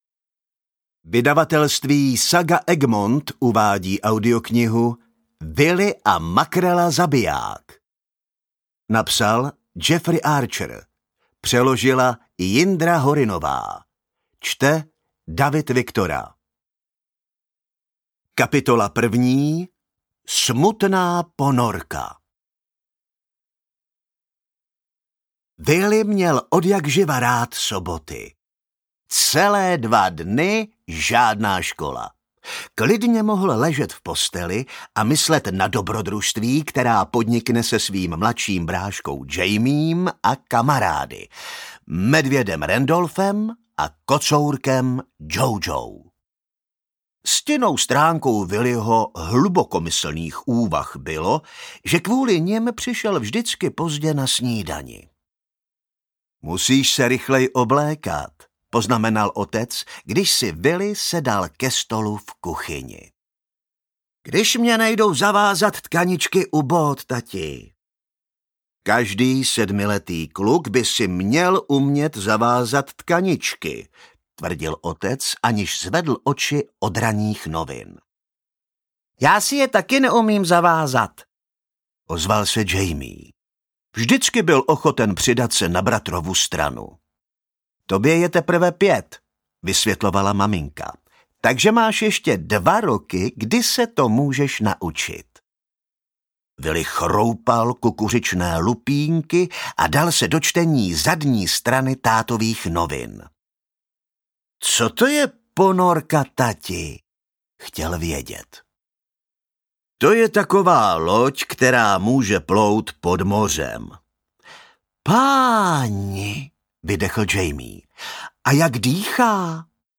Ukázka z knihy
willy-a-makrela-zabijak-audiokniha